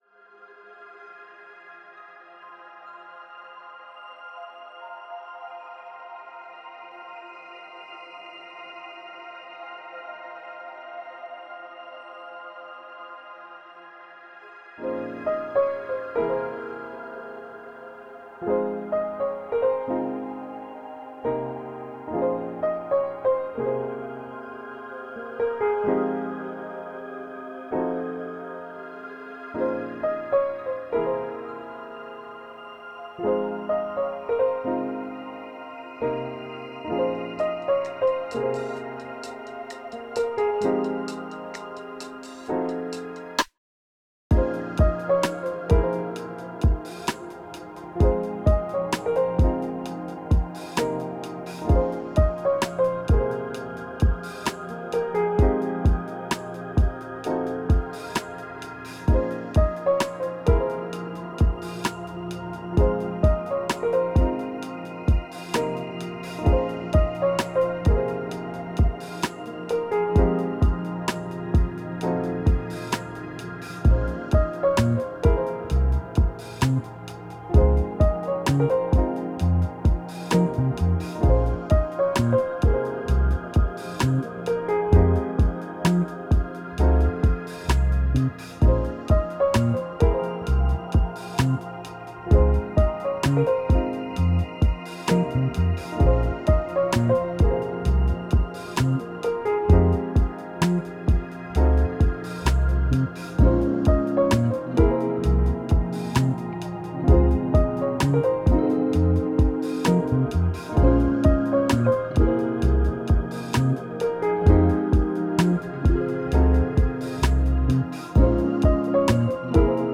Bridging in multiple genre influences..exploring transitions between sections...I rly do love producing.